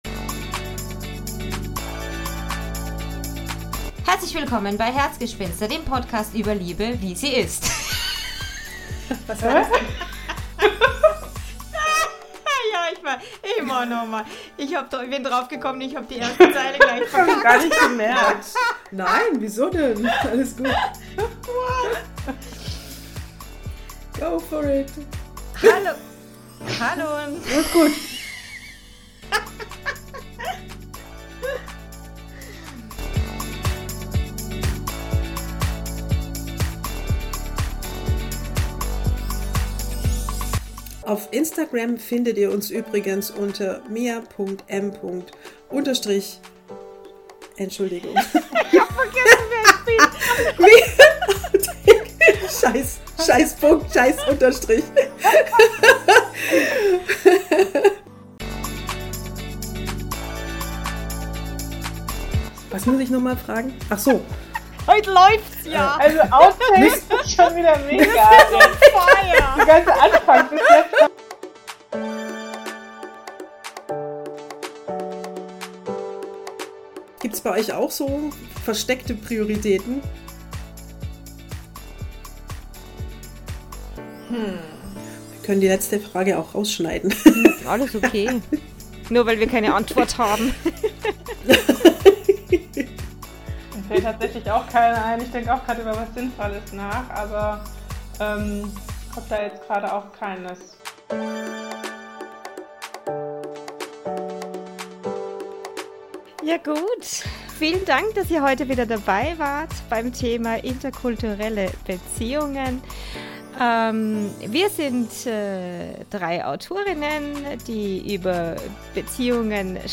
Outtakes 2024
Patzer, Pannen und jede Menge Lacher